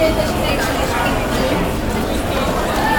In deze pilot meten we geluidsoverlast met zes sensoren op het Marineterrein.
Pratende mensen (mp3)
crowd-talking.mp3